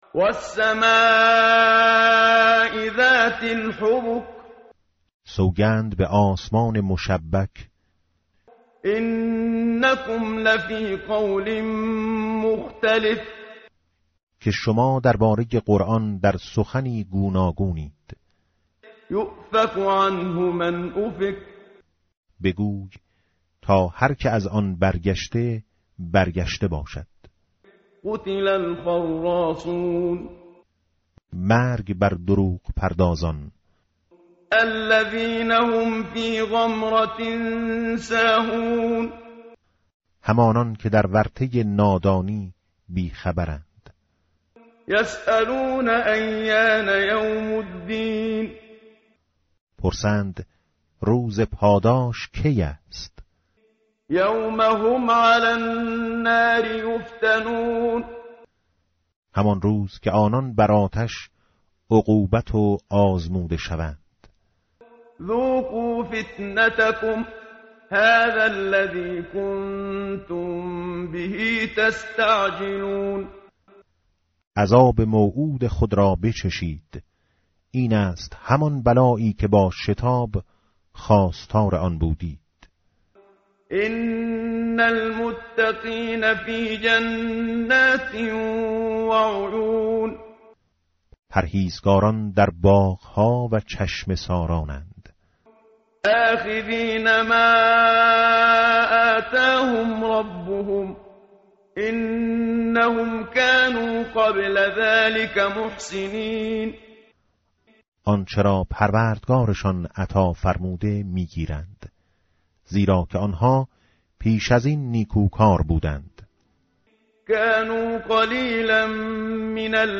متن قرآن همراه باتلاوت قرآن و ترجمه
tartil_menshavi va tarjome_Page_521.mp3